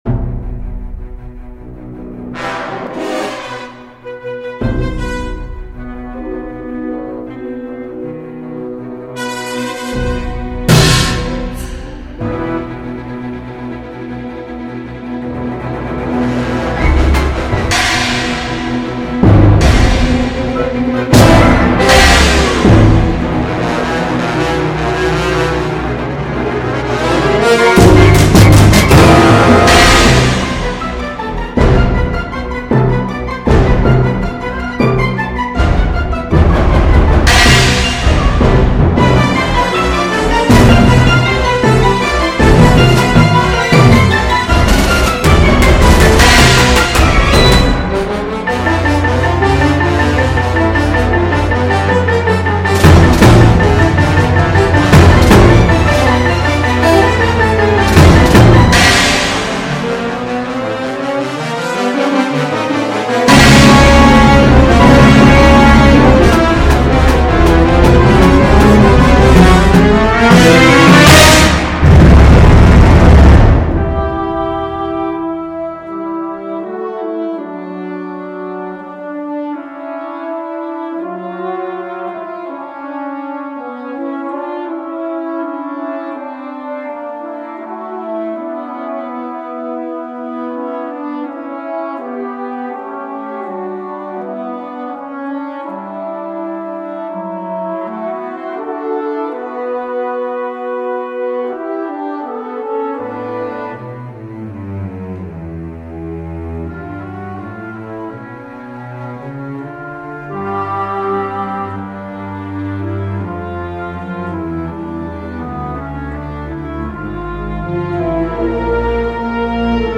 Sonic diarrhea - Orchestral and Large Ensemble - Young Composers Music Forum
Sonic diarrhea is a seven minute work consisting of random noises, groovy melodies, and the sonic equivalent of having a horrible stomach ache… it’s gr...